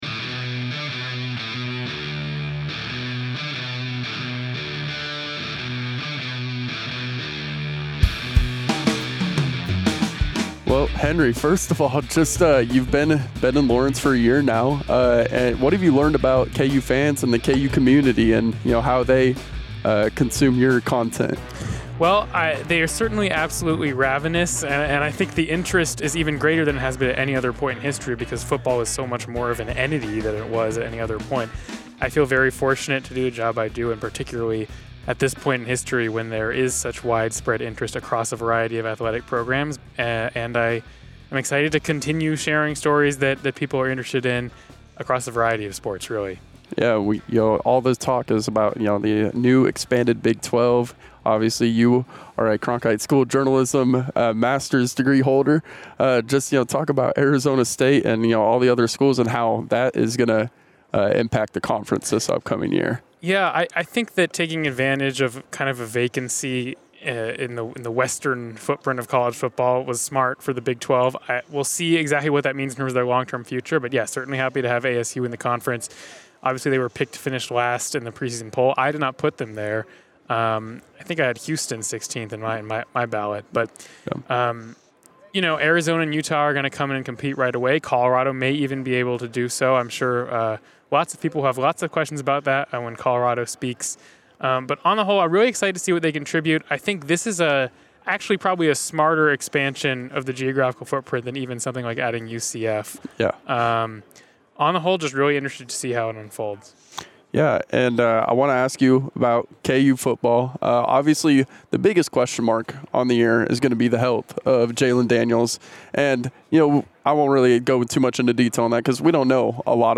Big 12 Media Days: Exclusive interviews
Las Vegas, NV – Western Kansas News sports staff spent this week at the Big 12 Media Days covering the Kansas State Wildcats and the Kansas Jayhawks, as well as acquiring exclusive interviews with other programs and members of the media.